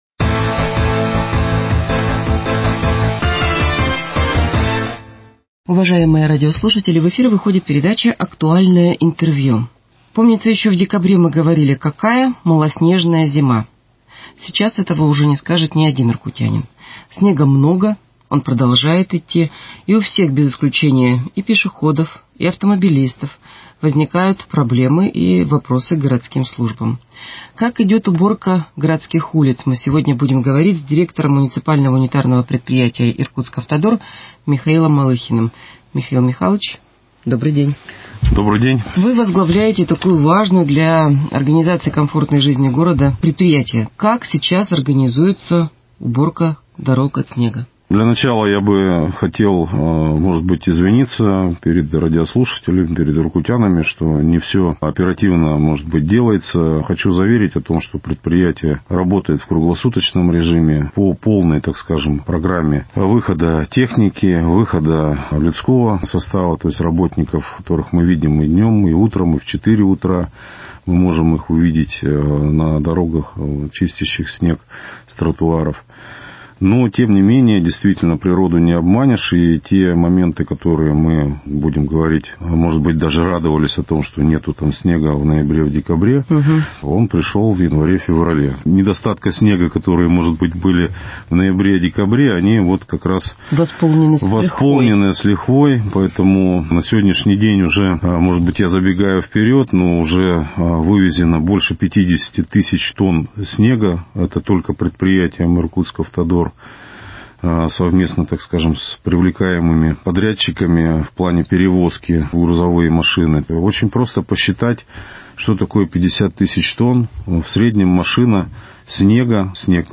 Актуальное интервью: Уборка улиц.